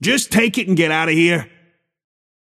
Shopkeeper voice line - Just take it and get outta here!